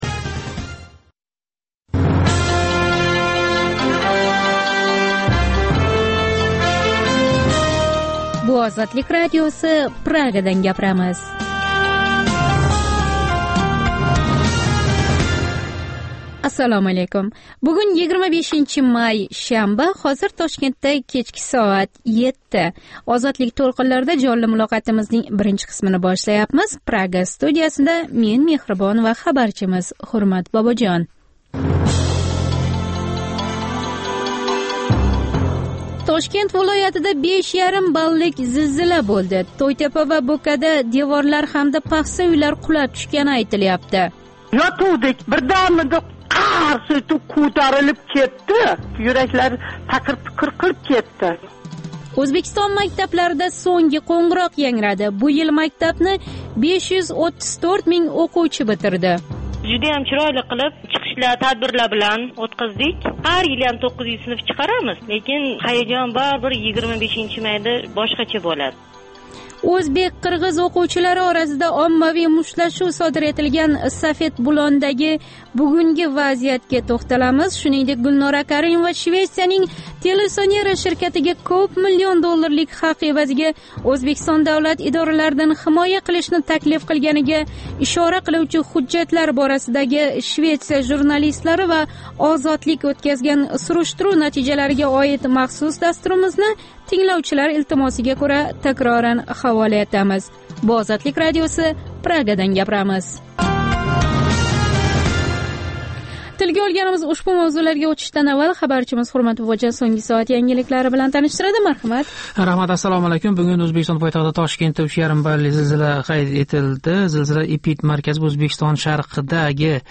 Жонли эфирдаги кечки дастуримизда сўнгги хабарлар, Ўзбекистон, Марказий Осиë ва халқаро майдонда кечаëтган долзарб жараëнларга доир тафсилот ва таҳлиллар билан таниша оласиз.